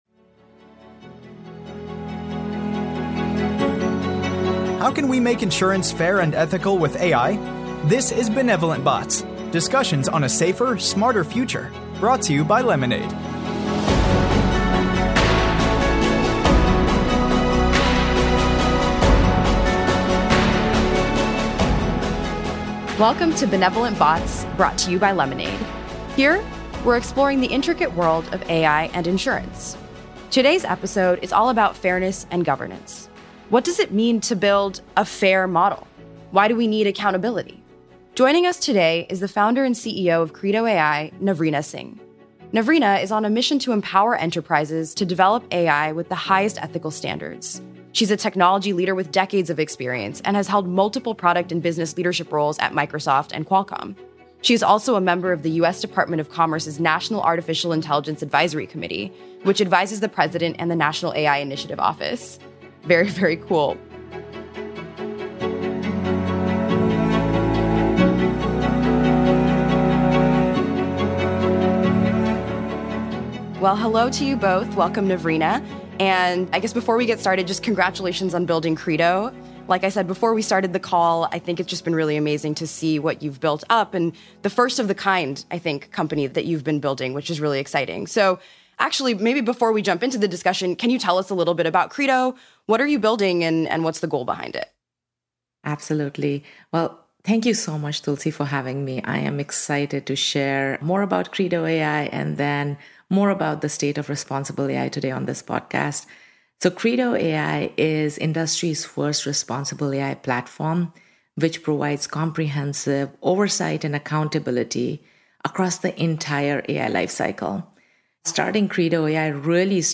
Experts from both fields will address the major questions facing the industry, shed light on some of the myths about AI, and discuss how AI ethics can be put in place in insurance. Join us for conversations on fairness, AI ethics, data ethics, transparency, accountability, privacy, and putting AI ethics into practice.